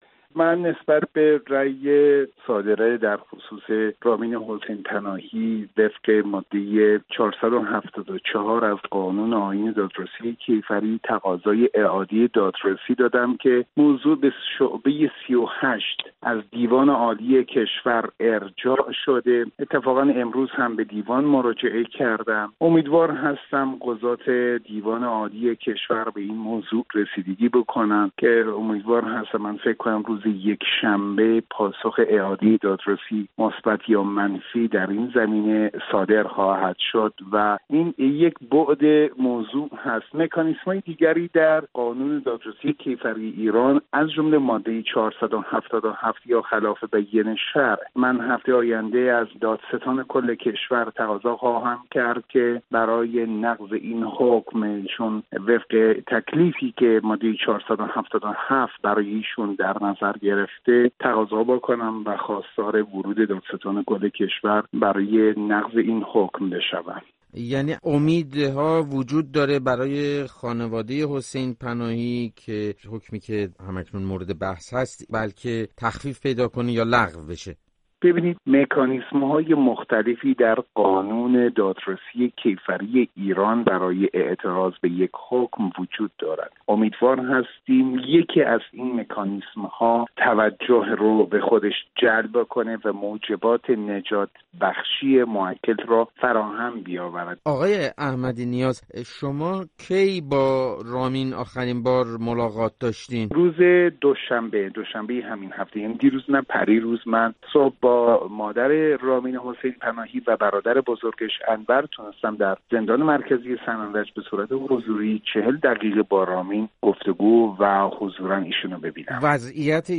در گفتگو با رادیو فردا وضعیت پرونده را تشریح کرده است.